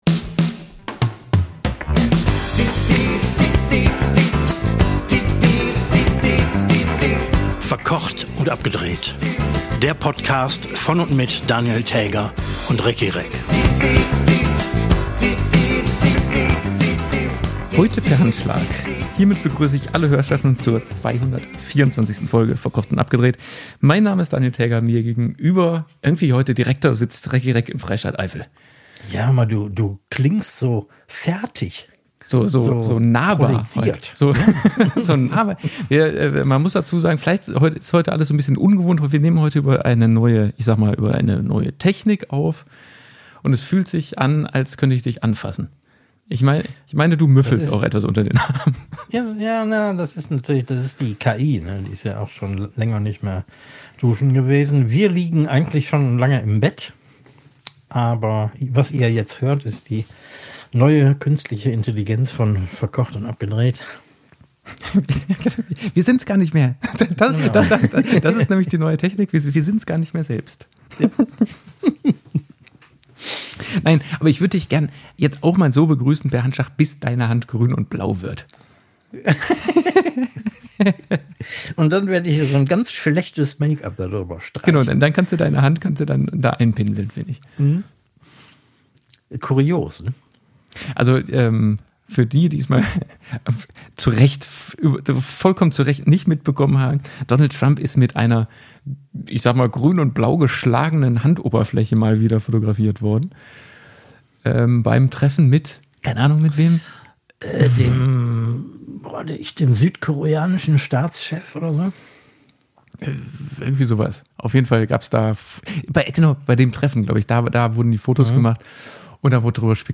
Mit Hilfe modernster Weltraumtechnik und Quantenphysik wurde dieser durchaus in die Jahre gekommene Retro-Podcast technisch auf das neueste Niveau gehoben, sodass man fast meinen könnte, die Beiden säßen sich gegenüber.
Und zwischendurch, weil mindestens einer der beiden zu schnell gegessen hat, grummelt es und blubbert es und man hört ab und zu ein kleines Lüftchen am Mikrofon vorbei entweichen…